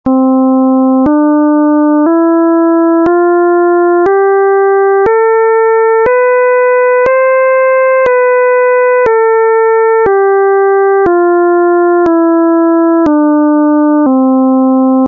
Βυζαντινή Μουσική - Κλίμακες
Διατονικὸ μαλακό
Οἱ ἤχοι ἔχουν παραχθεῖ μὲ ὑπολογιστὴ μὲ ὑπέρθεση ἀρμονικῶν.
diatonic_malako_262.mp3